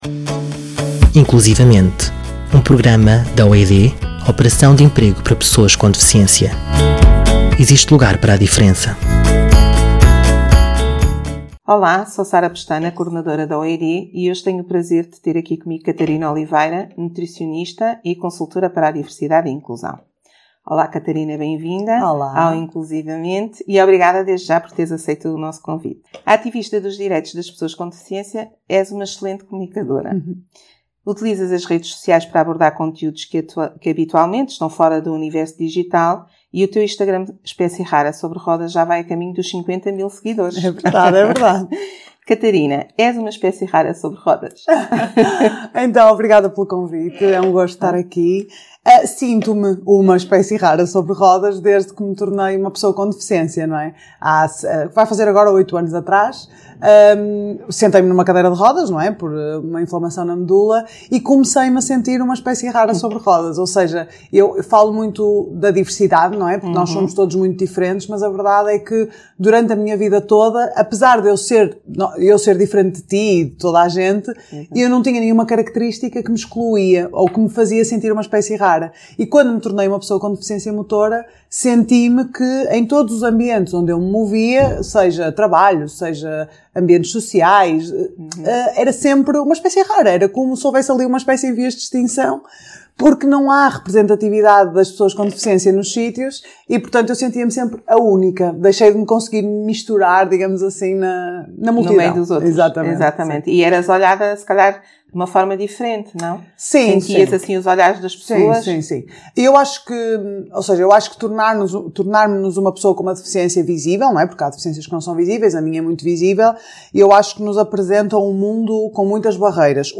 Um programa da OED – Operação de Emprego para Pessoas com Deficiência, de conversas sobre a inclusão.